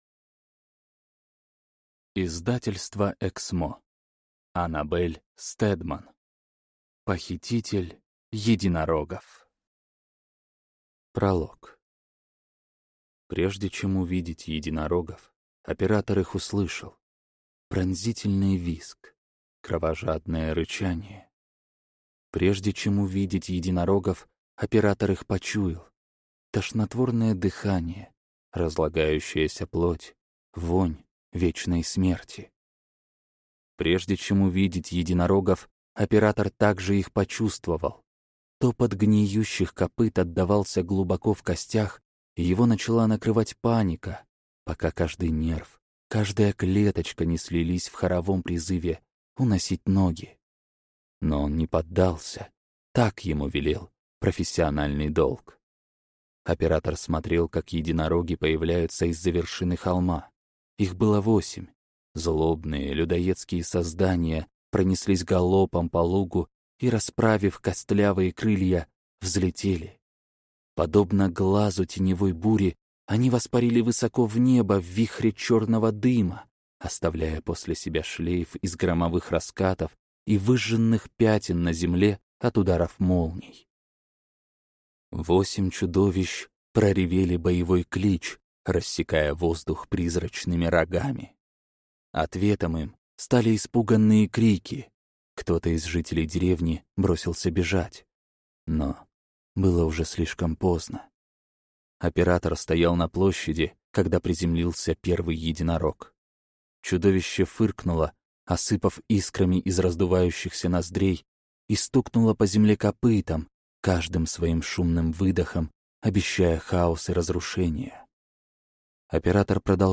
Аудиокнига Скандар. Похититель единорогов | Библиотека аудиокниг